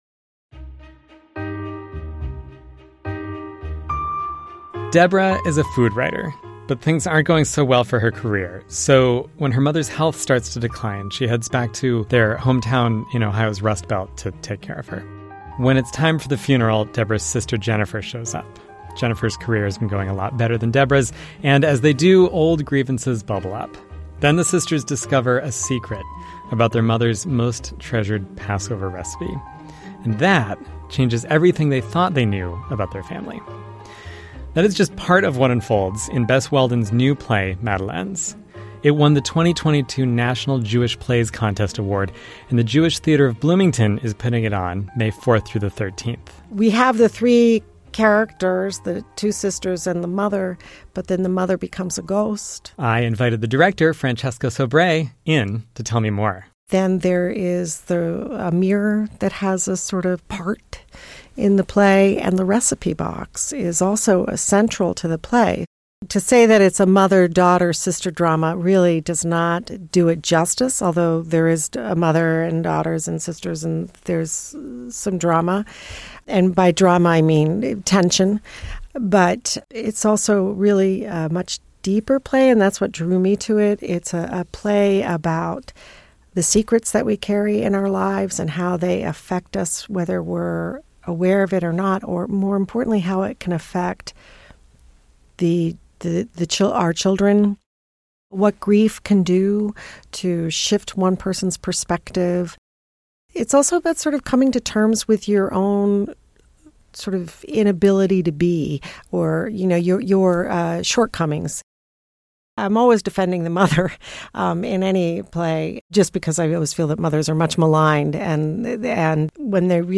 Arts and culture news, events, interviews, and features from around southern and central Indiana.